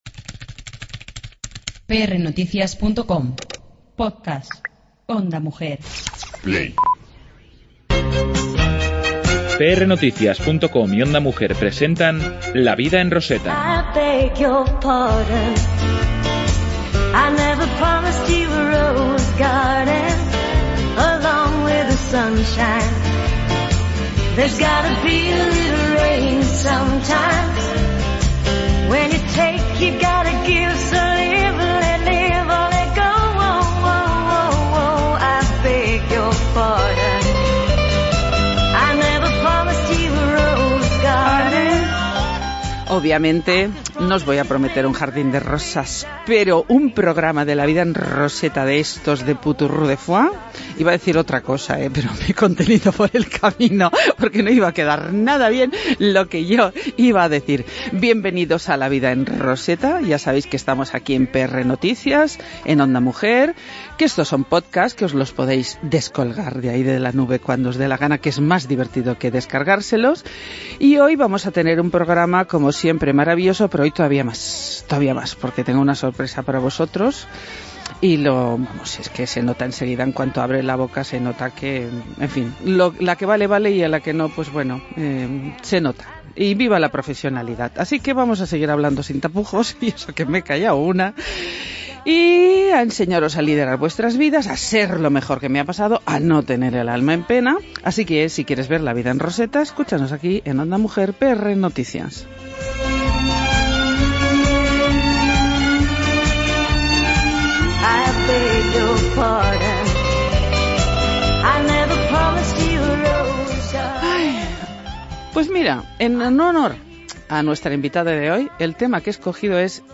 Hoy hemos entrevistado